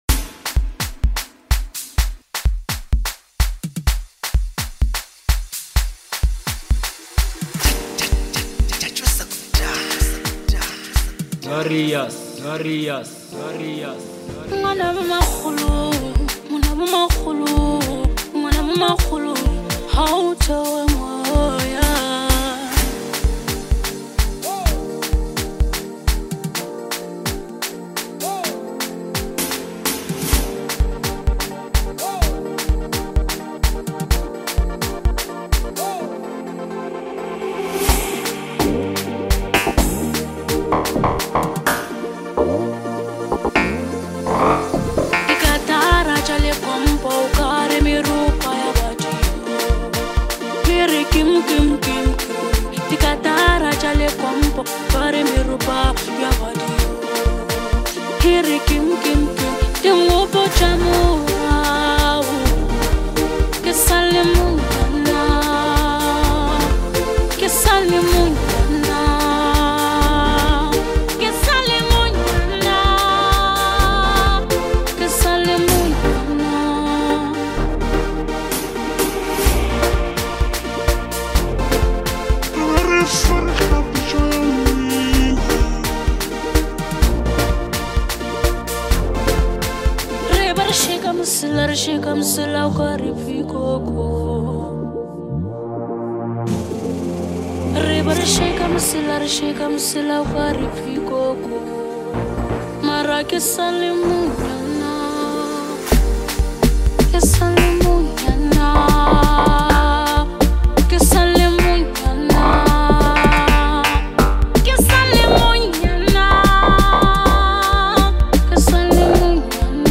a motivational banger